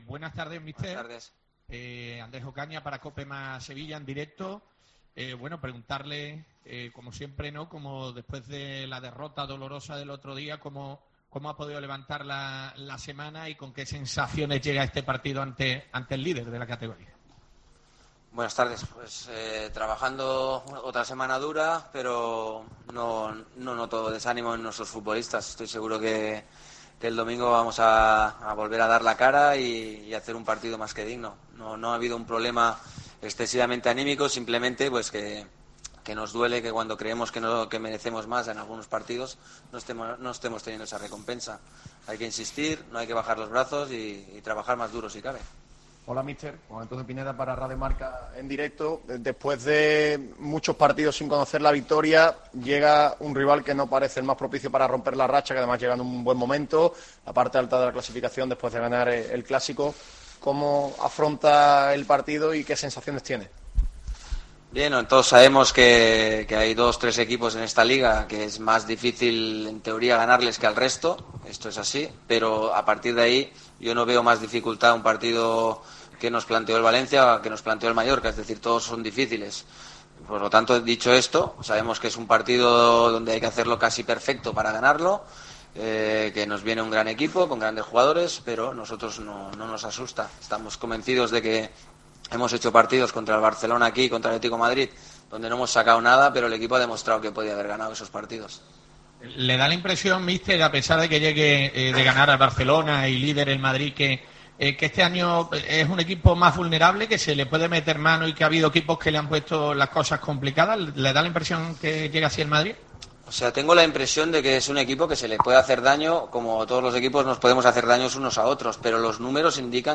Rubi, en la rueda de prensa previa al choque ante el Real Madrid